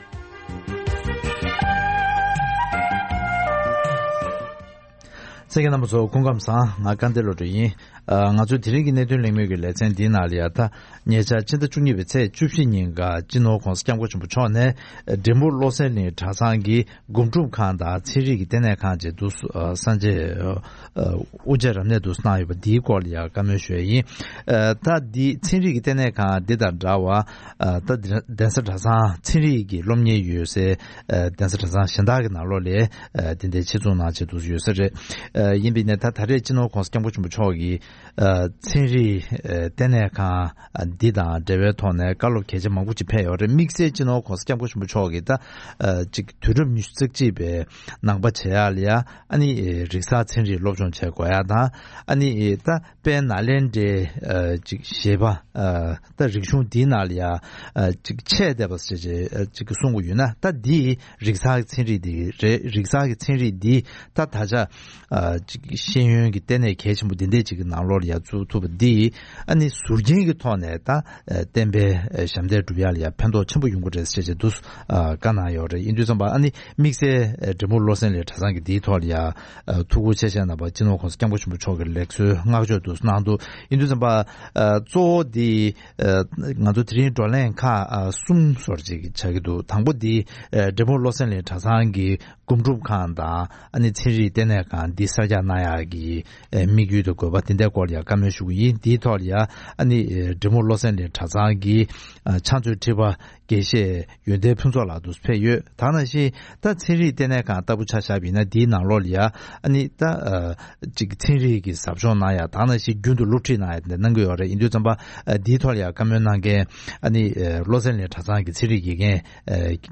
འབྲས་སྤུངས་བློ་གསལ་གླིང་གྲྭ་ཚང་གི་སྒོམ་སྒྲུབ་ཁང་དང་ཚན་རིག་ལྟེ་གནས་ཁང་དབུ་འབྱེད་ཐད་གླེང་མོལ།